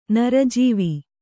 ♪ nara jīvi